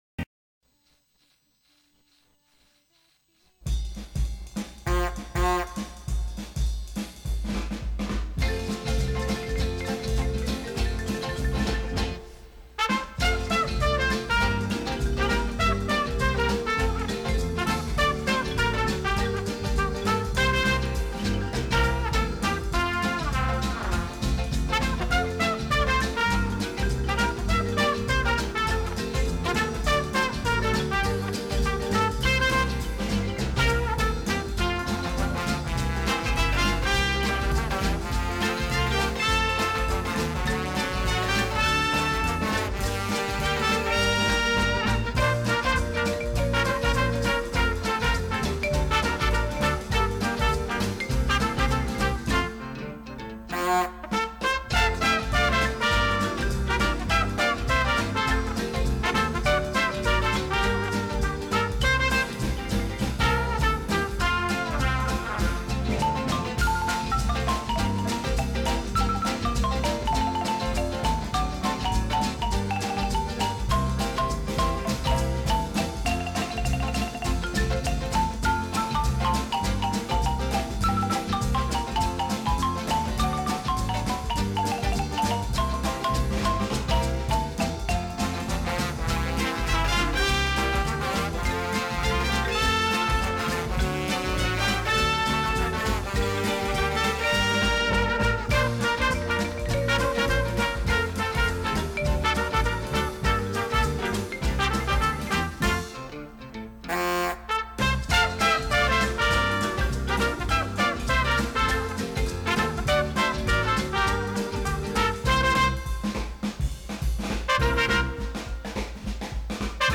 Eguerdiko Ahotsa magazine, informatibo, giza-kultural bat da, eta bertan prentsa errepasoa, elkarrizketak, kaleko iritziak, kolaboratzaileen kontakizunak, musika, agenda eta abar topatu ditzazkezu.